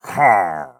掠夺者：咕哝
空闲时随机播放这些音效
Minecraft_pillager_idle2.mp3